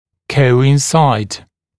[ˌkəuɪn’saɪd][ˌкоуин’сайд]совпадать